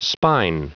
Prononciation du mot spine en anglais (fichier audio)
Prononciation du mot : spine